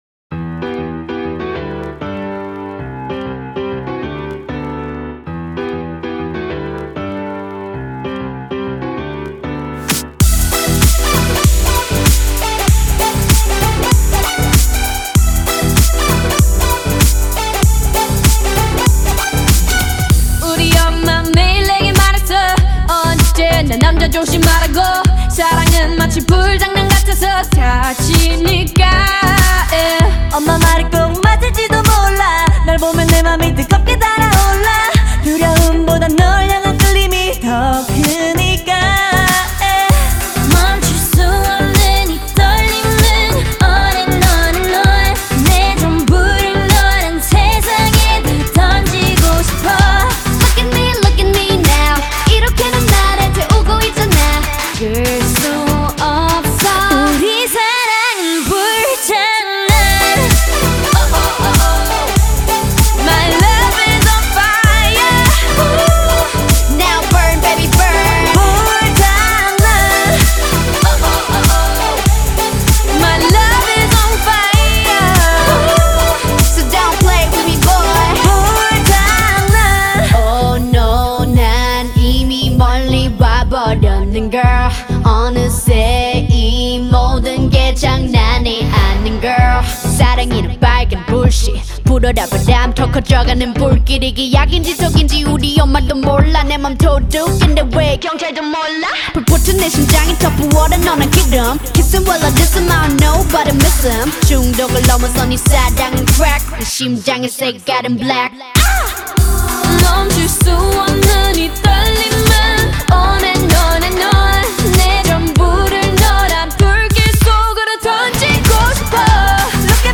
South Korean girl group